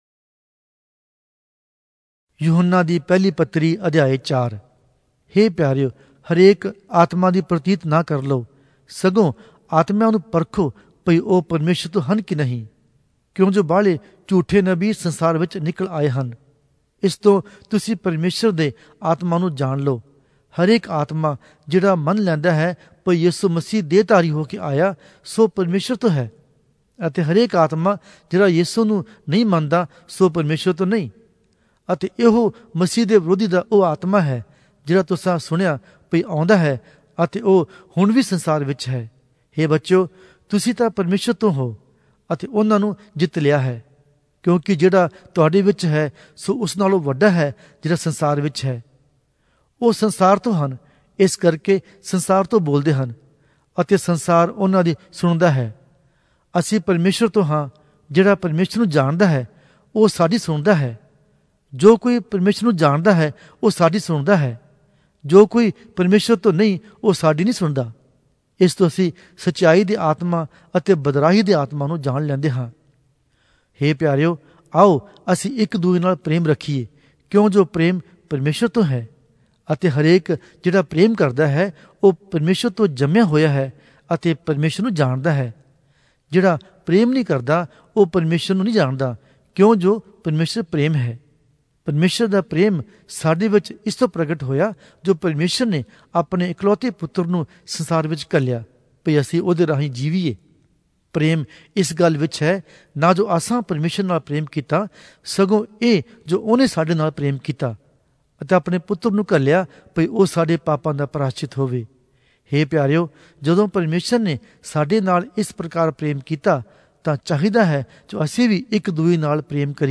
Oriya Audio Bible - 1-John 5 in Bhs bible version